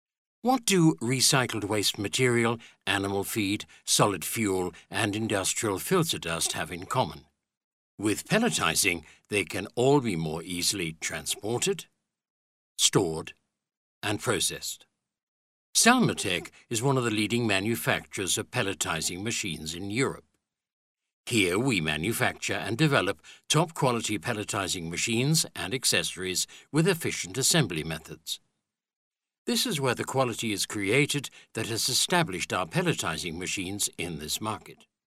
britisch
Sprechprobe: Industrie (Muttersprache):